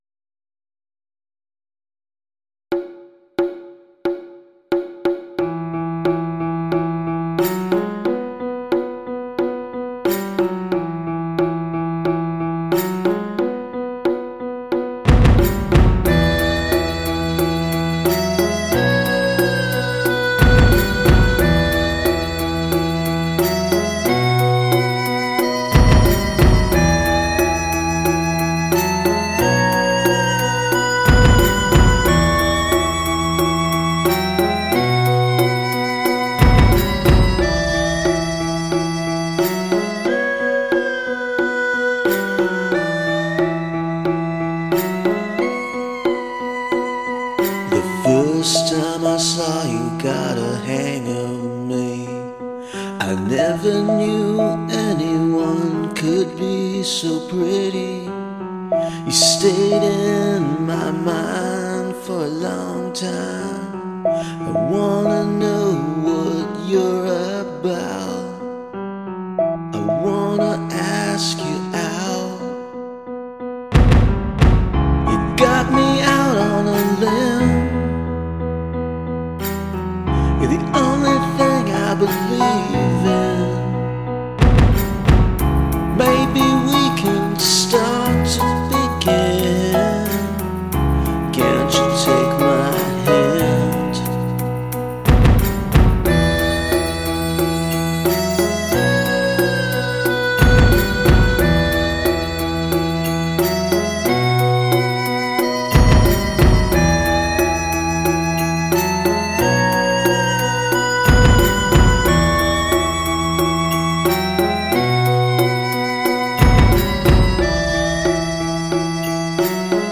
with more of an Asian flair